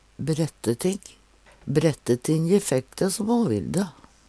Sjå òg brettug (Veggli) bretteleppa (Veggli) vanndin (Veggli) Høyr på uttala